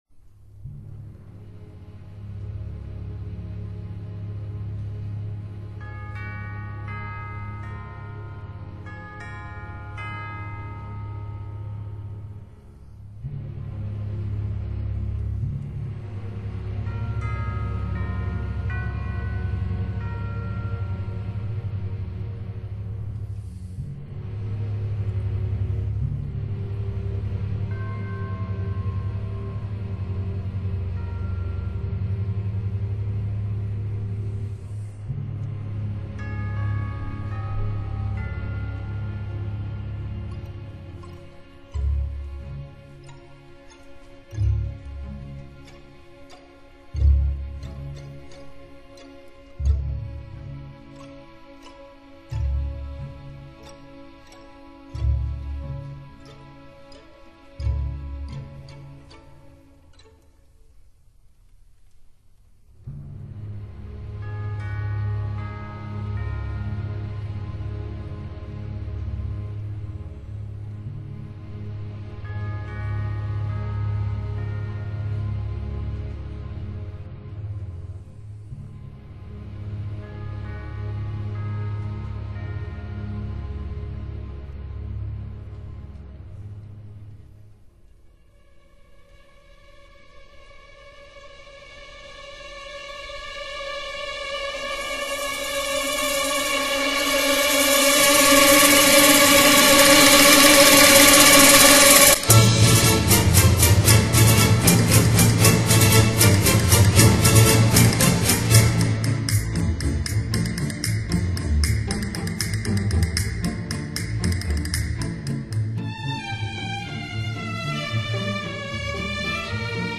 录    音:Moscow,State Conservatory,2 & 3/1998